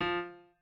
piano2_26.ogg